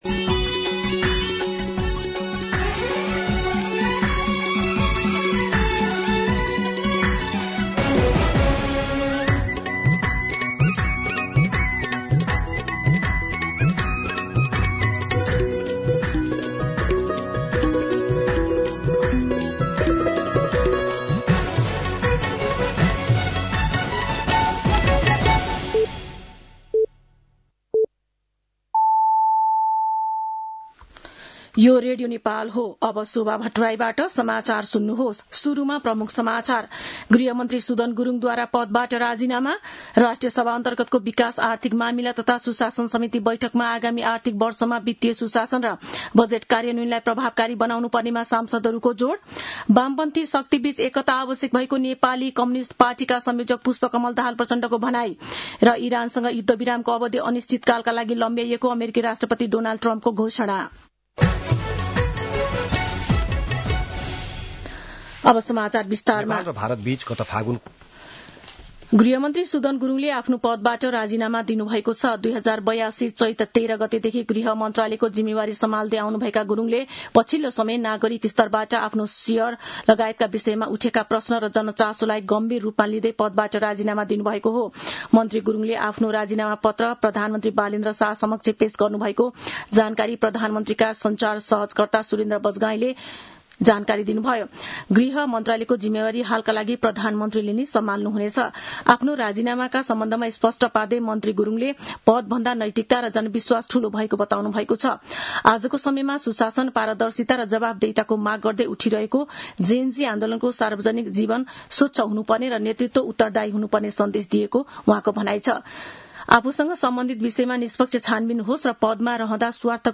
दिउँसो ३ बजेको नेपाली समाचार : ९ वैशाख , २०८३
3pm-News-09.mp3